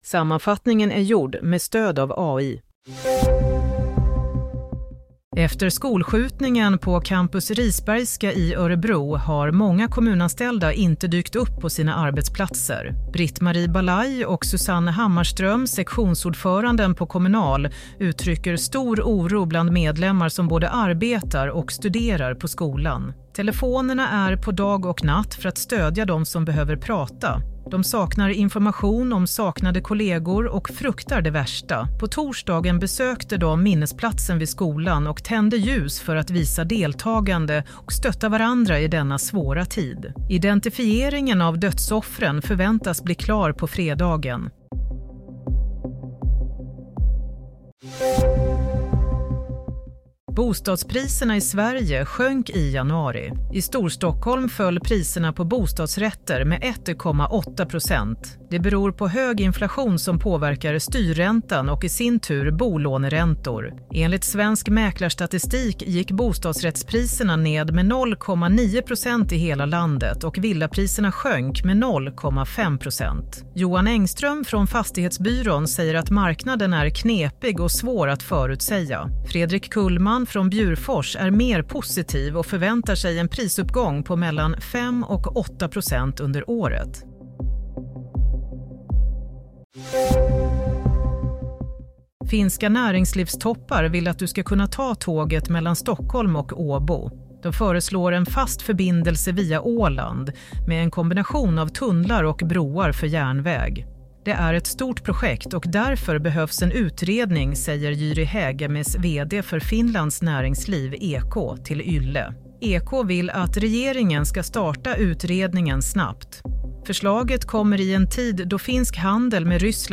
Nyhetssammanfattning - 7 februari 08:00